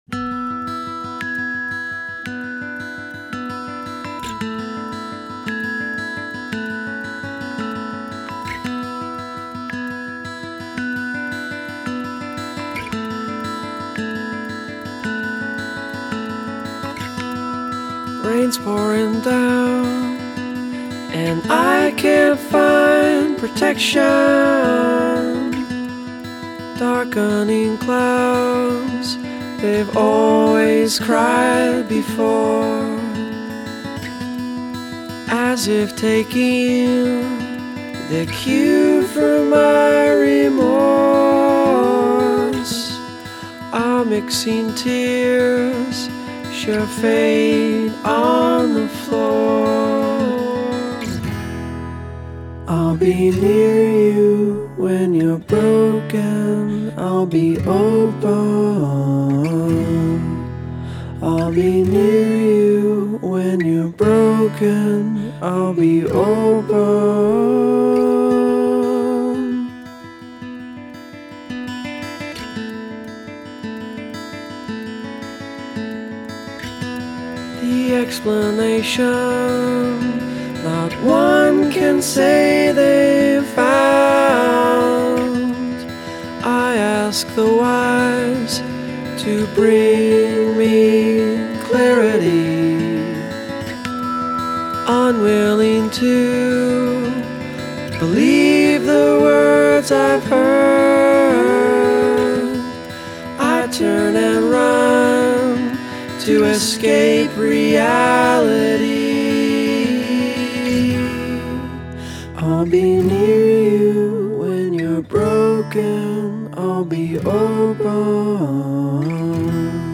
Tags2000s 2007 alternative Canada Rock